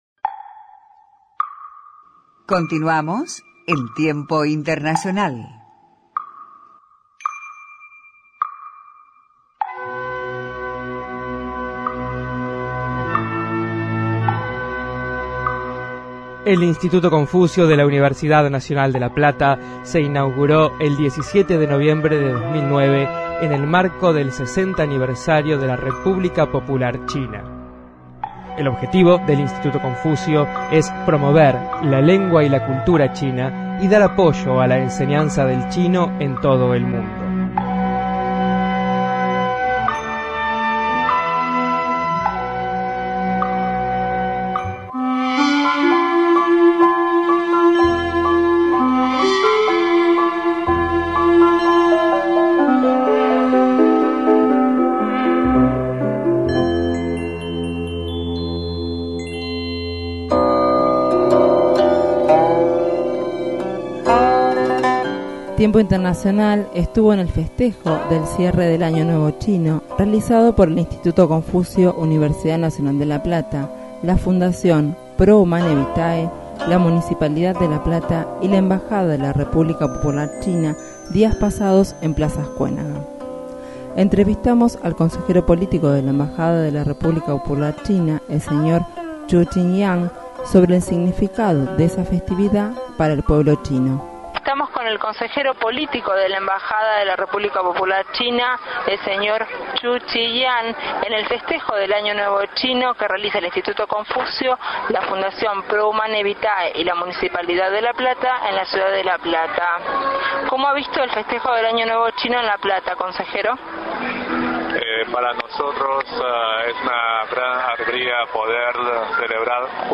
entrevistas a